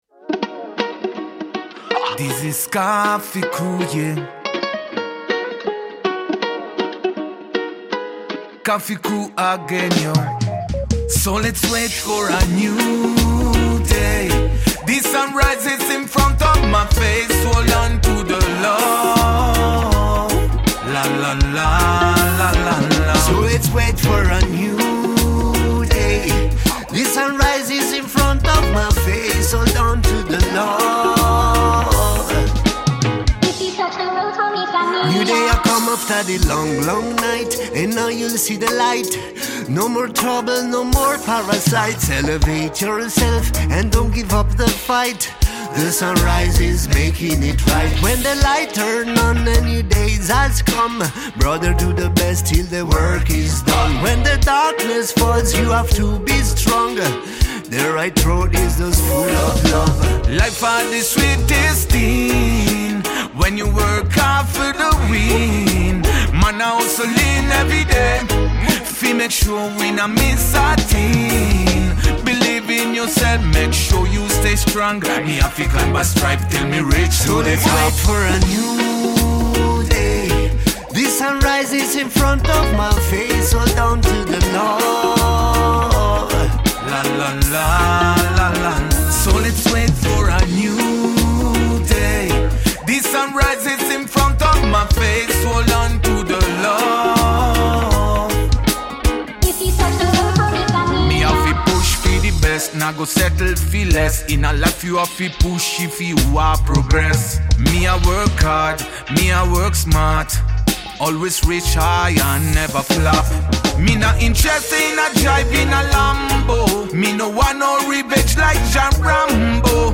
Artista-a-la-Vista-Intervista-Quartiere-Coffee.mp3